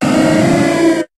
Cri de Noarfang dans Pokémon HOME.